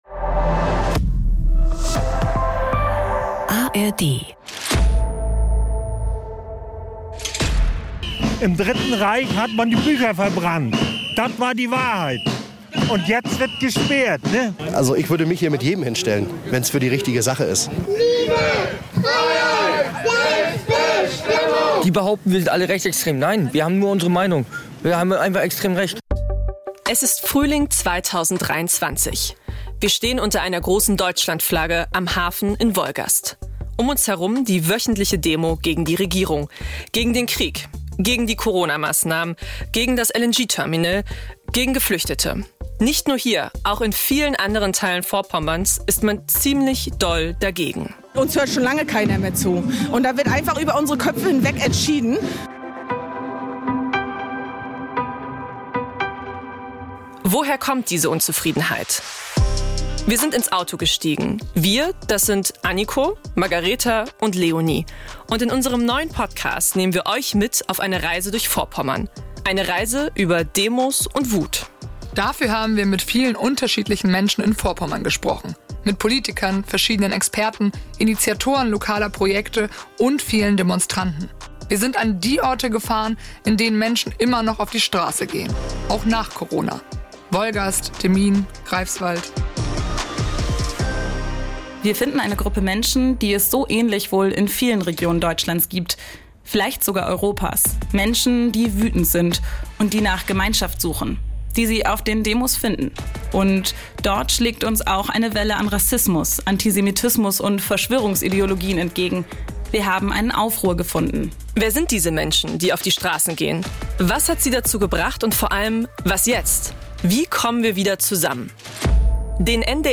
Trailer: Aufruhr - Über Wut, Demos & Zusammenhalt